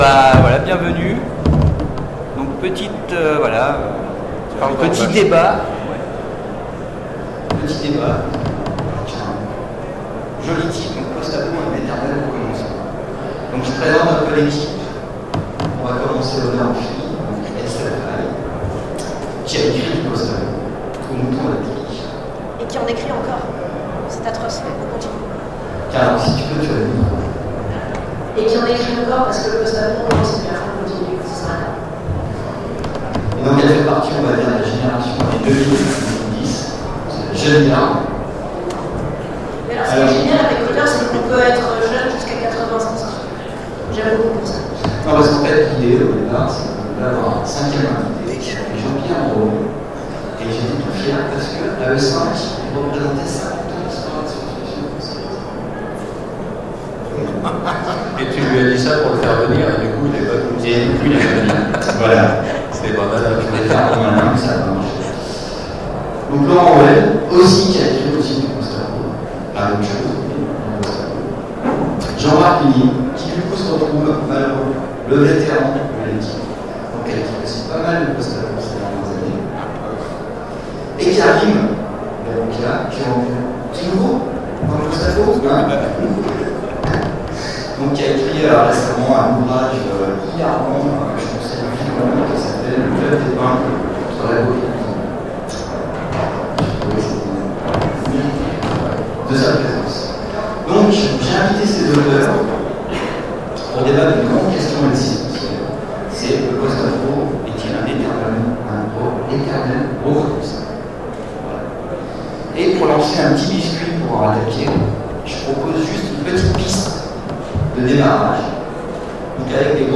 Octogônes 2016 : Conférence Post-Apo, un éternel recommencement
octogones_2016_Conference_Post_Apo_un_eternel_recommencement.mp3